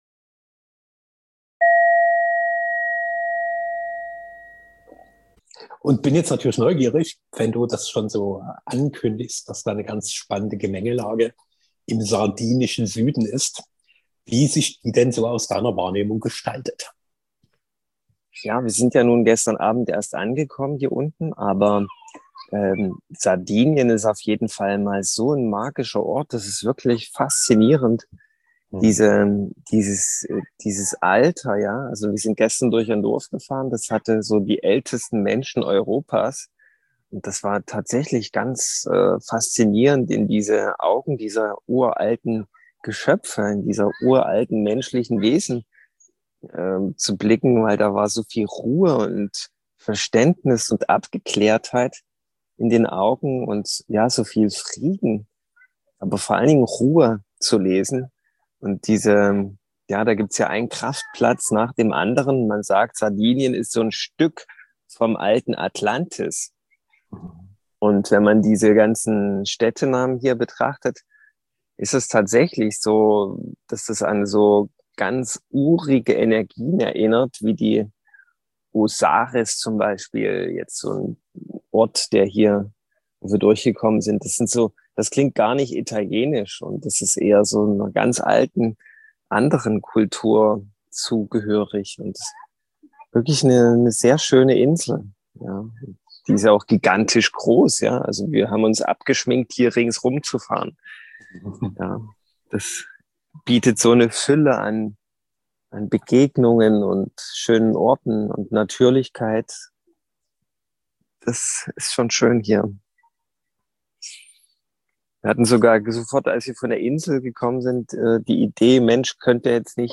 * * * * * Das Konzept ist einfach: Wir treffen uns im Zoom-Raum.
Keine gestochene Audioqualität.